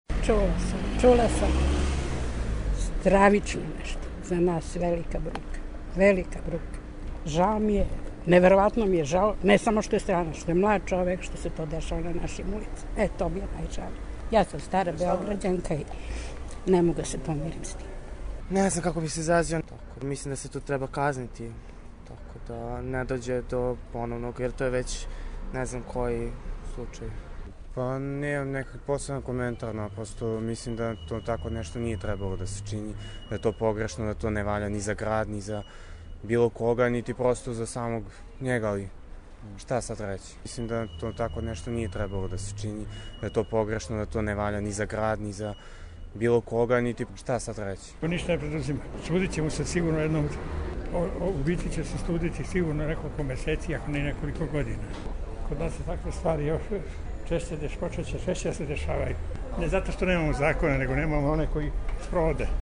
Anketa Beograd smrt Francuza